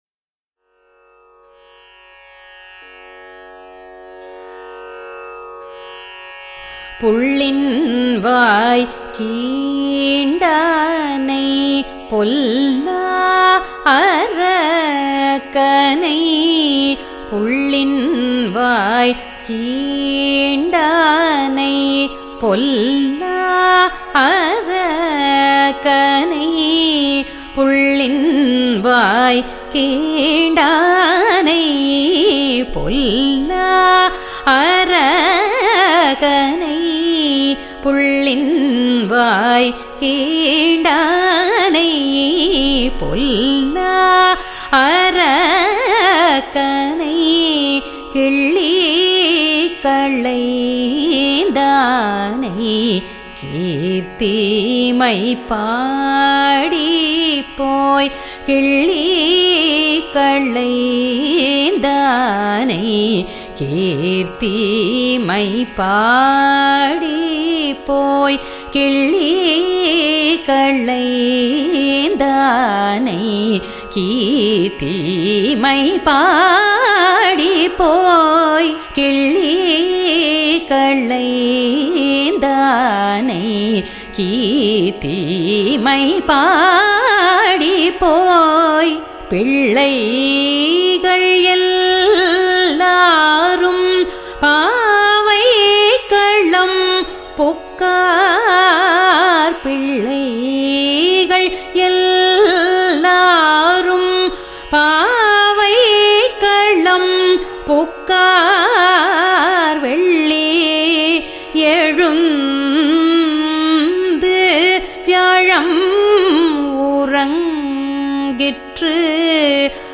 Atana
rUpakam